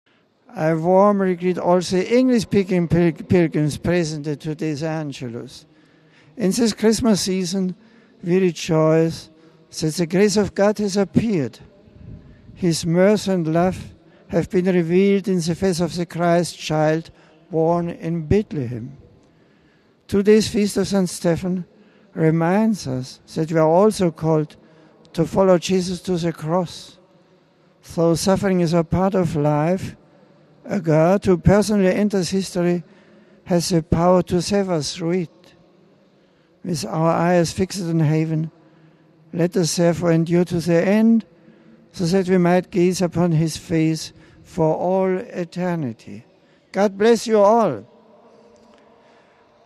The appeal for prayers and solidarity for hostages came as the Pope greeted pilgrims and tourists who turned up in St. Peter's Square on the feast of St. Stephen to pray the midday ‘Angelus’ prayer with him.
Pope Benedict also addressed English-speaking pilgrims present at the 'Angelus' of Dec. 26.